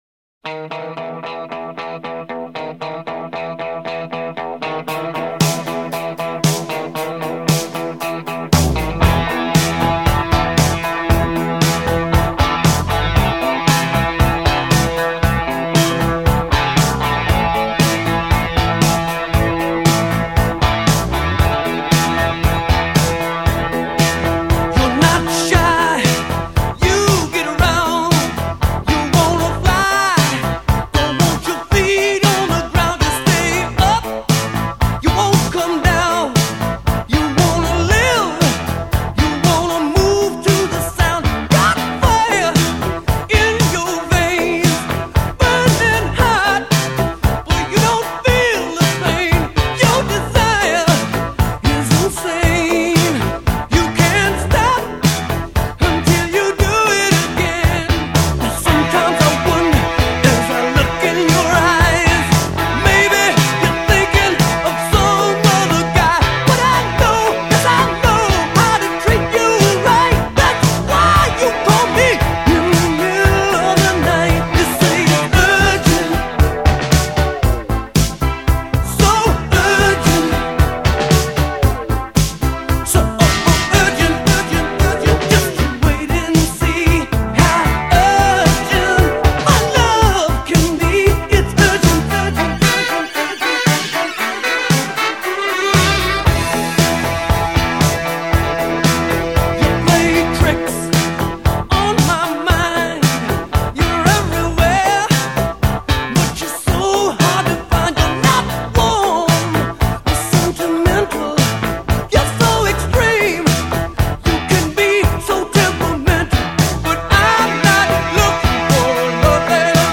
solo de saxophone